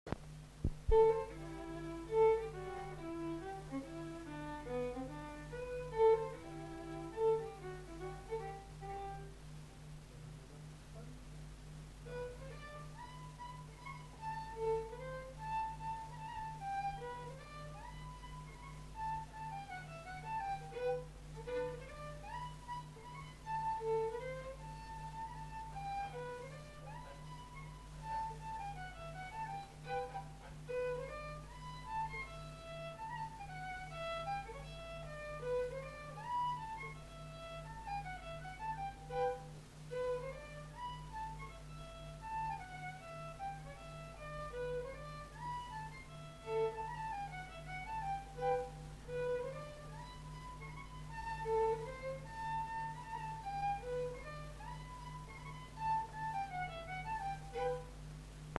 Aire culturelle : Gabardan
Genre : morceau instrumental
Instrument de musique : violon
Danse : mazurka